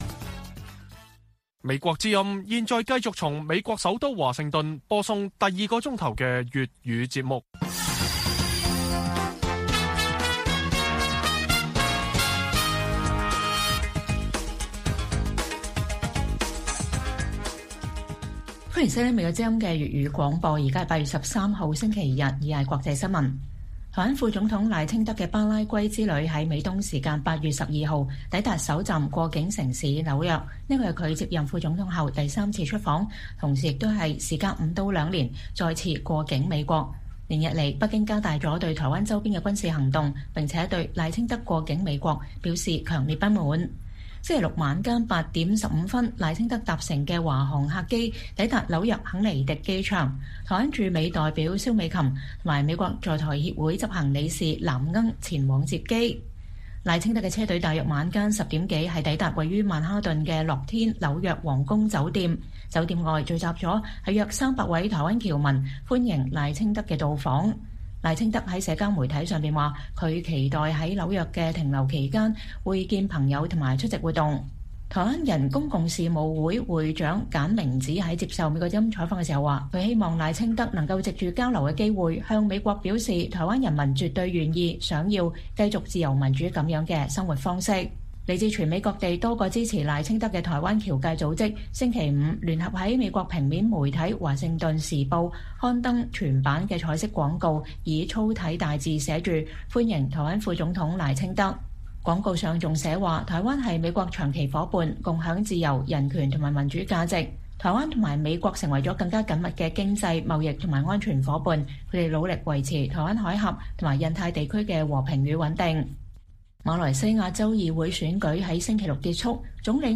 粵語新聞 晚上10-11點
北京時間每晚10－11點 (1400-1500 UTC)粵語廣播節目。內容包括國際新聞、時事經緯和英語教學。